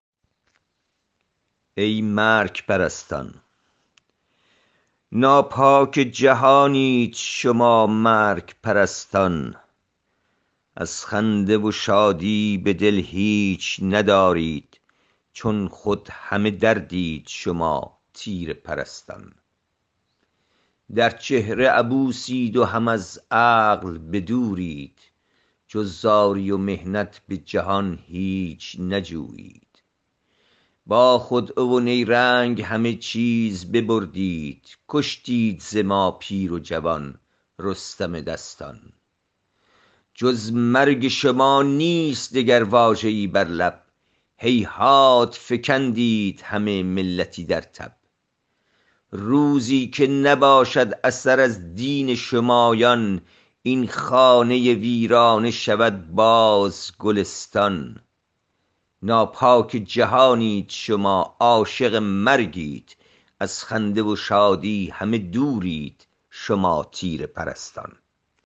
این شعر را با صدای شاعر از این جا بشنوید